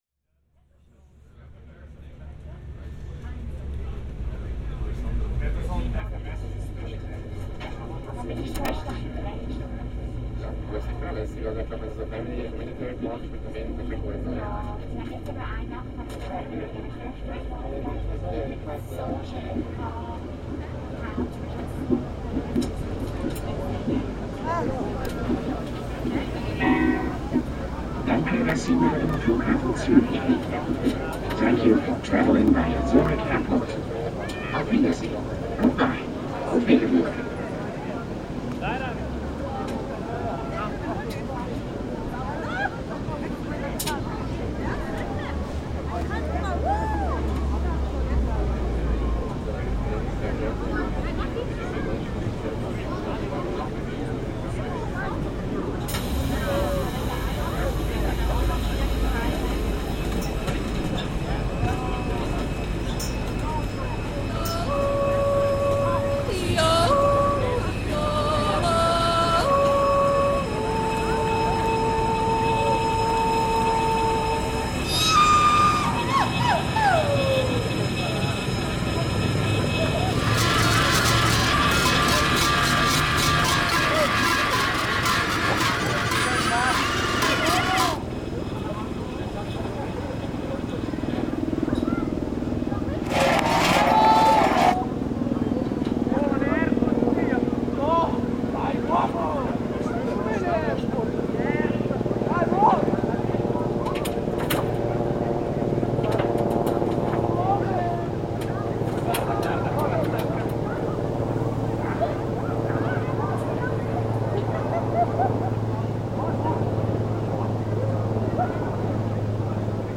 7’55’’, stereo
By collecting and remixing found material such as field recordings, traditional Swiss music, and audio from subcultures, I investigate how sound plays an important role in rituals, community building, representation, and its potential as a medium of documentation.
A peaceful Swiss forest soundscape / Chüeh-Löckler: Traditional swiss alphorn song / Church bells at the world economic forum / Crossroad (Lindenplatz, Zürich Altstetten) between heritage-listed buildings and new developments with apart- ments, a club, and restaurants / “Freiheitstrychler“ ringing bells at demonstration against COVID measures / Helicopters bring world leaders to the World Economic Forum (Davos, Switzerland) / Learning to Yodel – Made Easy: Part 1 Introduction / Swim, chill, grill at Werdinsel, natural recreational area in Zurich / Swiss national anthem / Zurich Airport shuttle bus/ Zurich City Forest at 6am / Zürcher Südkurve Hooligans / 1st Street Parade (Zürich, 1992)